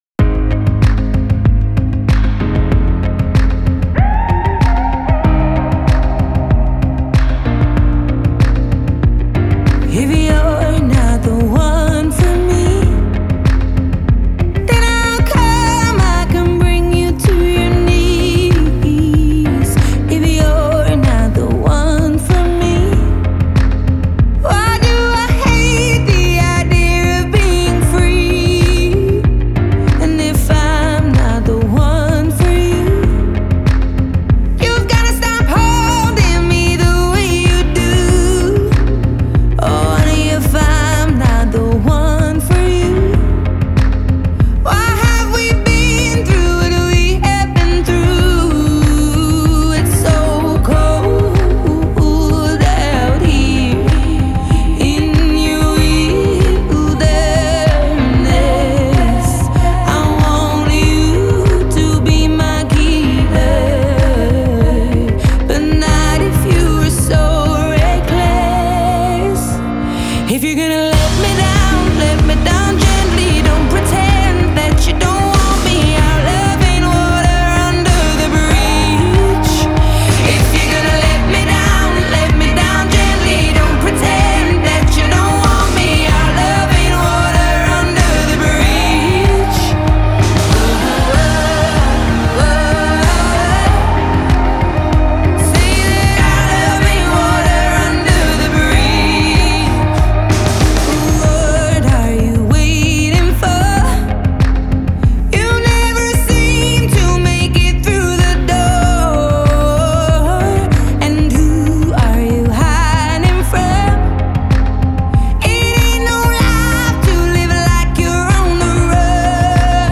Genre: Pop,Blues